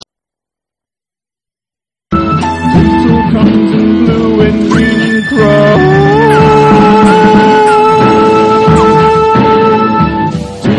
baroque Christmas tunes